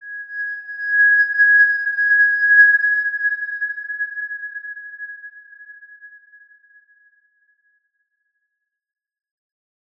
X_Windwistle-G#5-pp.wav